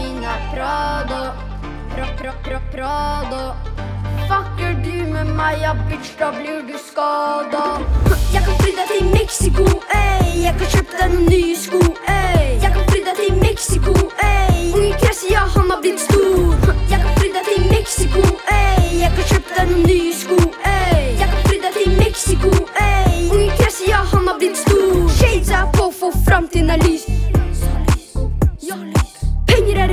Жанр: Иностранный рэп и хип-хоп / Поп / Рэп и хип-хоп